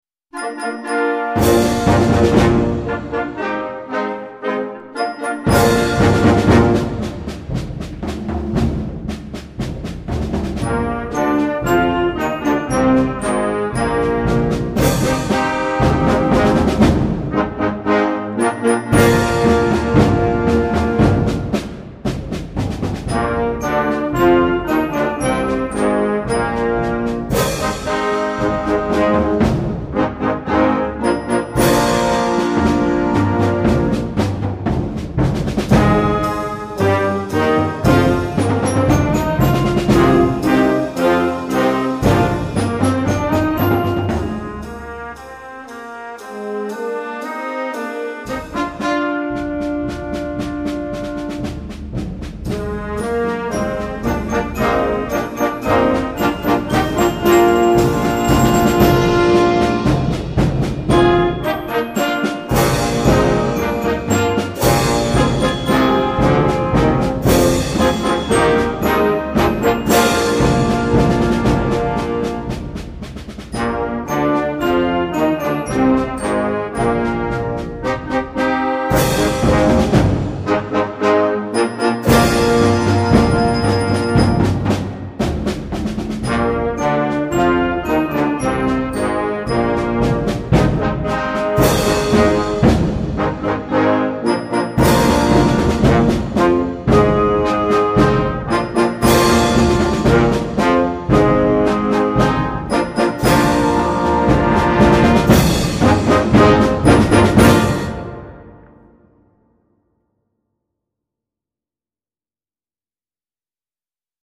Concert Band
will bring an exciting big sound to a very young band